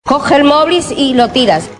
En la fauna televisiva de los programas del diario, aparece una mujer harta del movil de su marido.